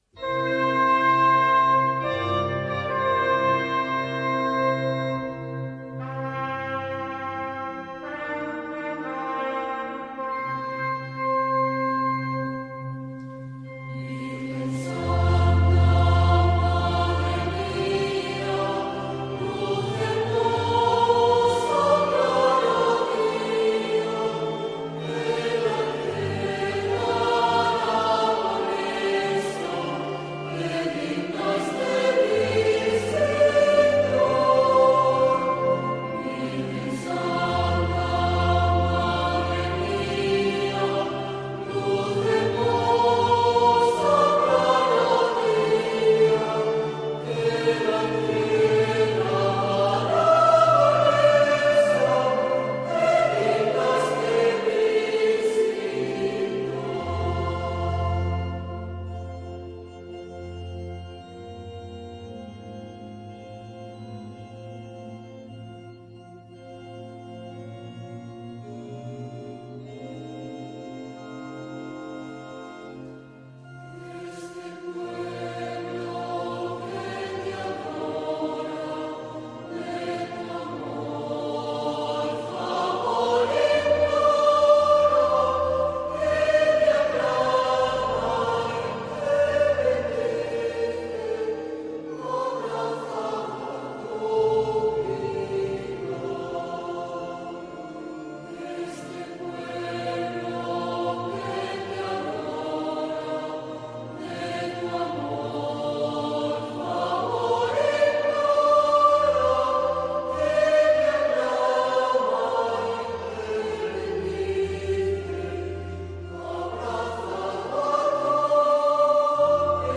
Himno-a-la-Virgen-del-Pilar-canto-Popular.mp3